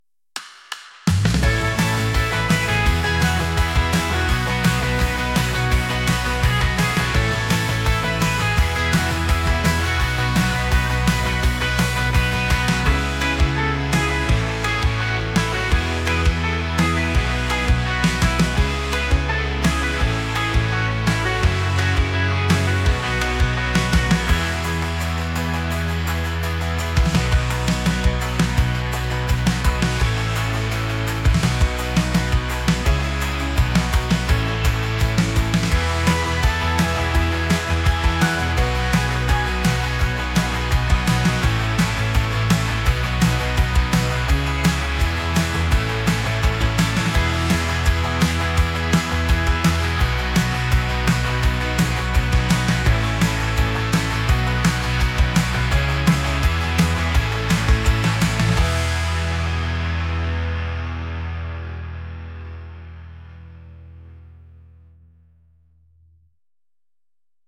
pop | upbeat | catchy